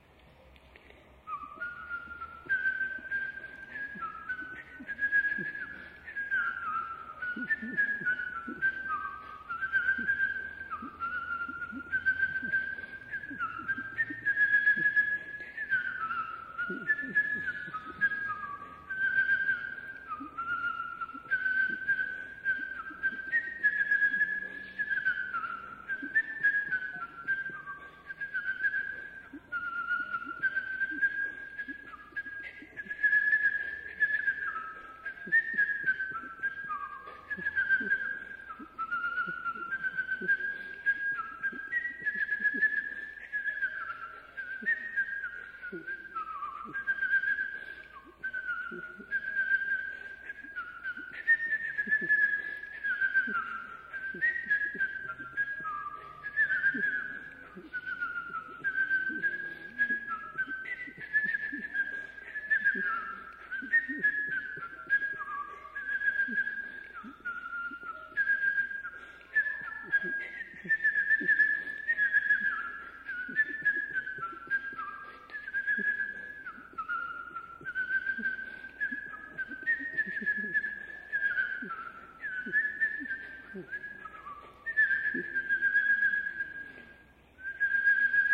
originally released on cassette in 1996.
whistling!